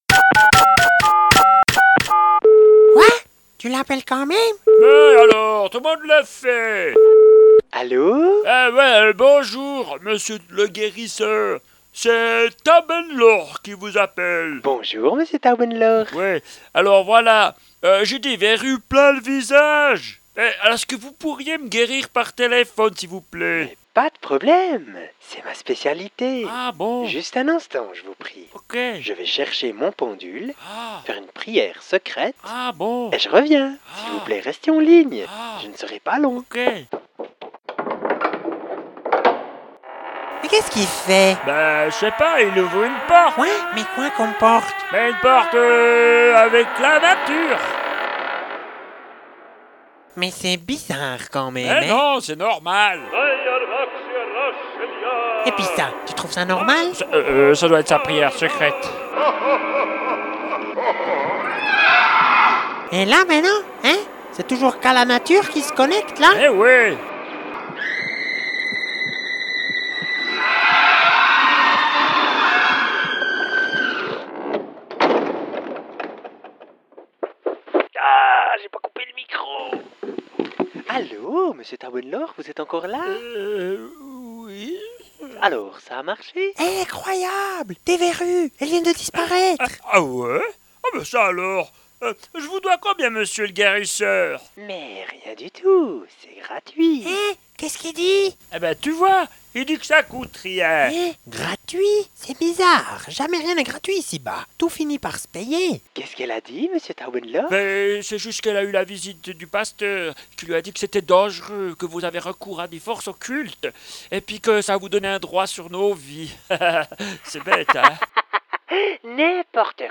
Les sketches :